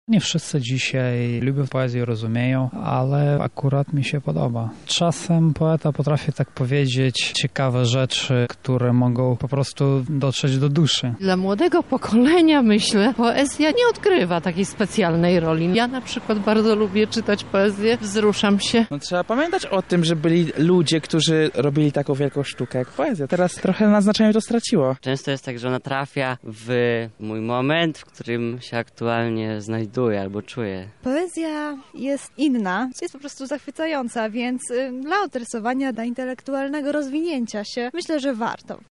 Zapytaliśmy lublinian, co sądzą o o czytaniu poezji.